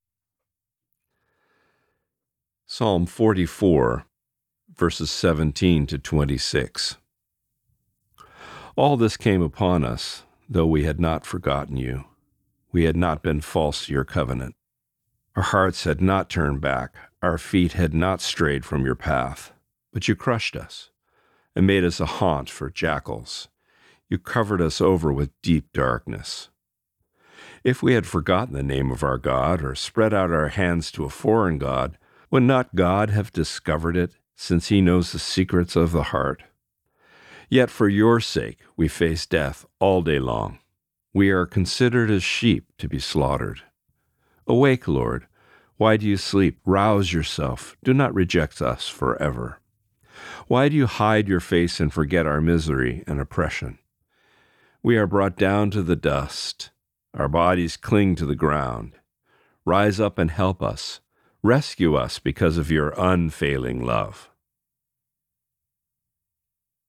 Reading: Psalm 44:17-26